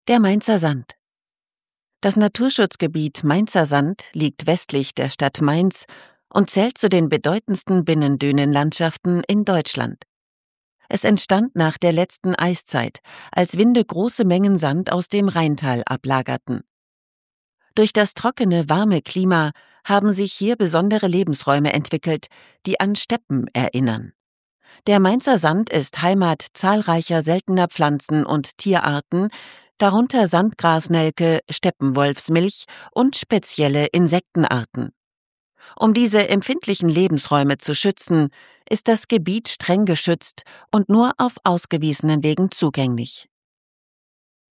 hell, fein, zart, dunkel, sonor, souverän
Mittel minus (25-45)
Sachtext
Doku